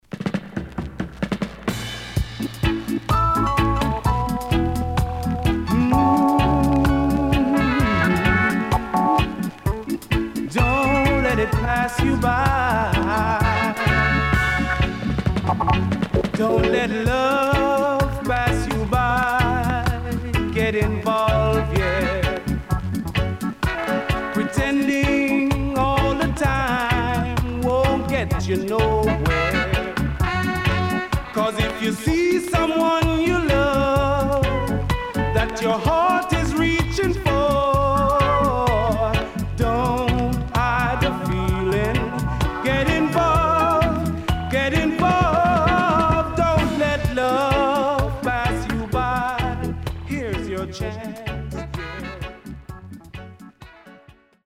Good Vocal